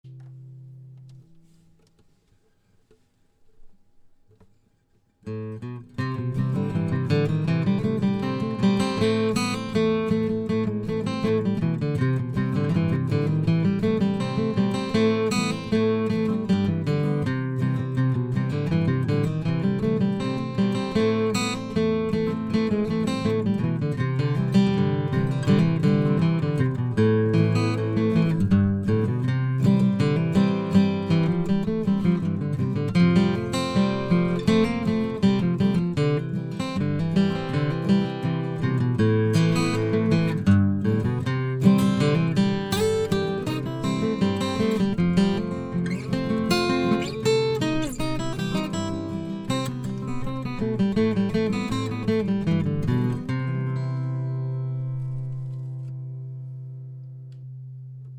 This audio is a version of Billy in the Lowground with a low volume rhythm recorded using an AudioTechnica pro37 mic and Tascam Neo recorder.
BillyInTheLowground.InfinityD-18eco.mp3